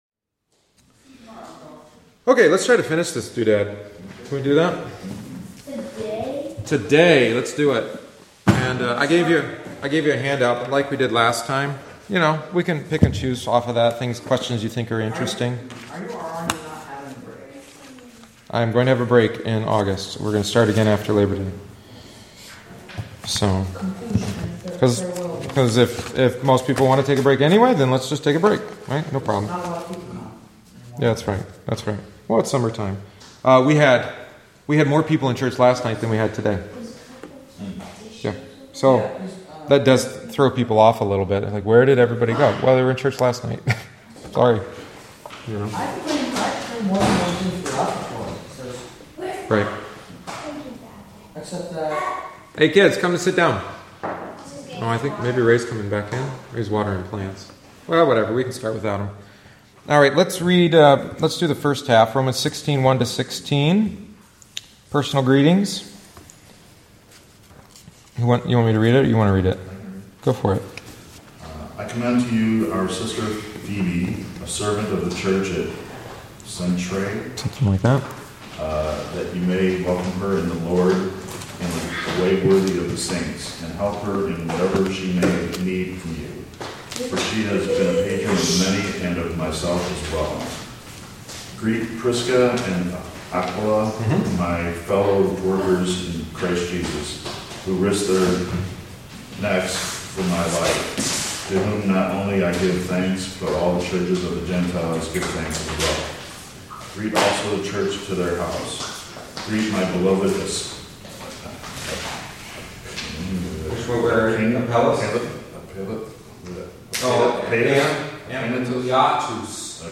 The following is the thirty-seventh and final week’s lesson.